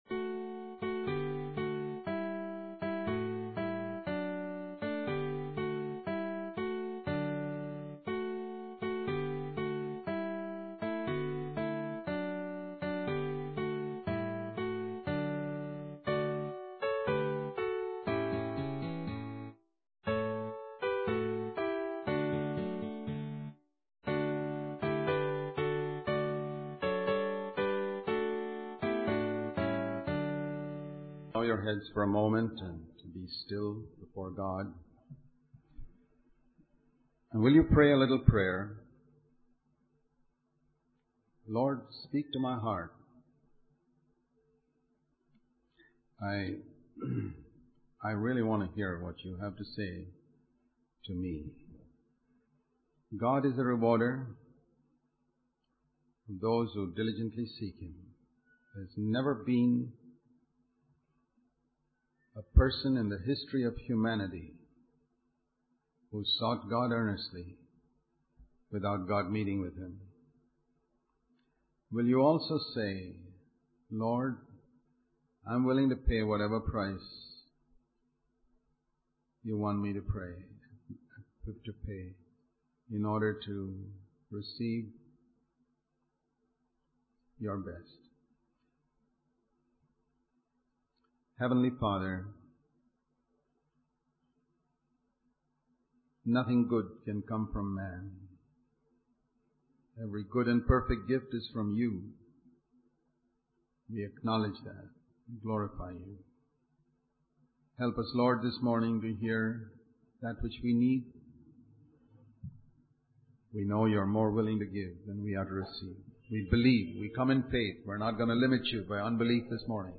In this sermon, the speaker emphasizes the importance of not fighting for our rights in any area of life. He refers to Luke 16:13, where Jesus states that we cannot serve both God and wealth.